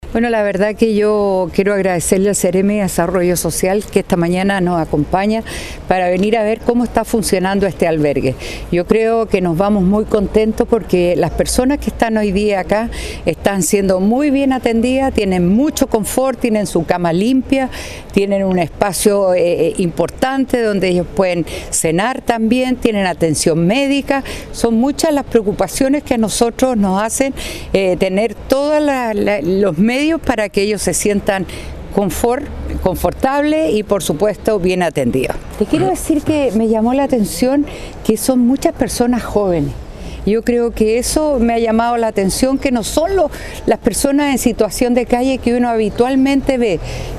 La alcaldesa de Viña del Mar, Virginia Reginato dijo que estas personas están siendo muy bien atendidas, destacando que hay muchas personas jóvenes en esta situación.